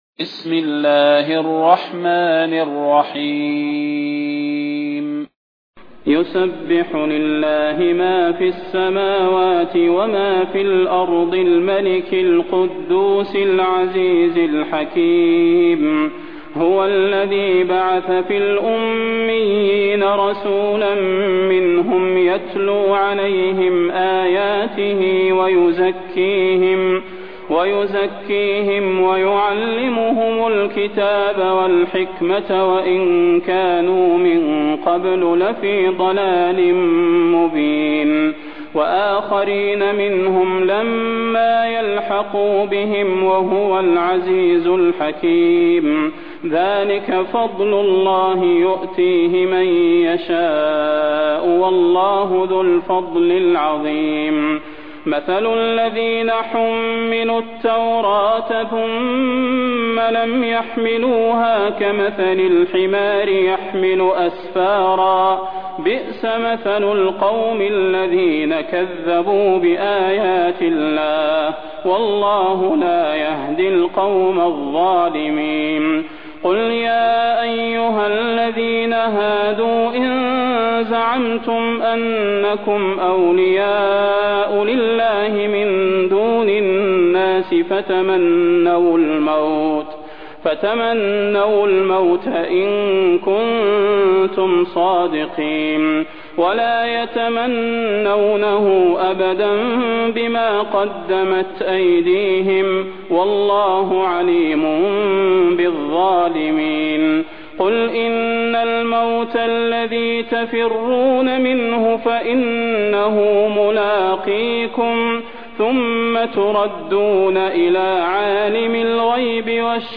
المكان: المسجد النبوي الشيخ: فضيلة الشيخ د. صلاح بن محمد البدير فضيلة الشيخ د. صلاح بن محمد البدير الجمعة